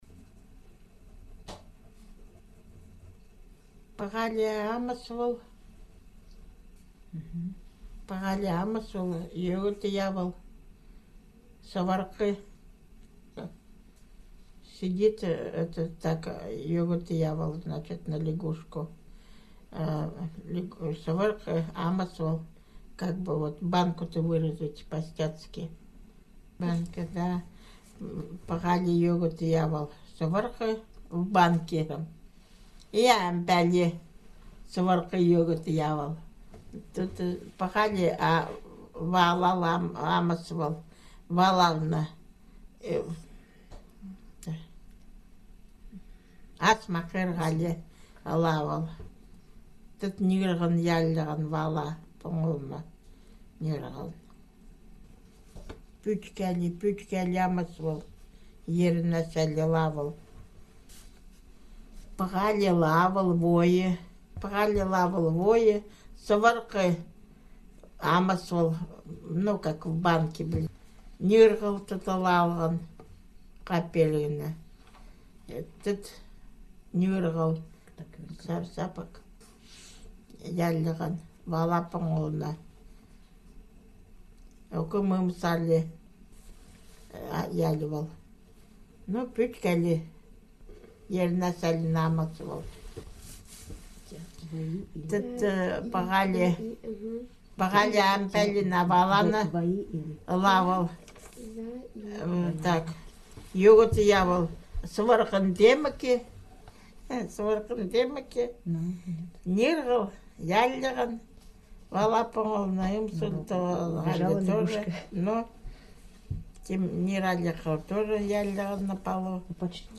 These Eastern Khanty texts were recorded in 2007 in the upper and lower Vasyugan river areas, and in the Alexandrovo Ob’ river communities. The texts were narrated by the male and female Vasyugan Khanty and Alexandrovo Khanty speakers to other Khanty speakers and to the researchers, who also spoke limited Khanty and offered occasional interjections to the narration.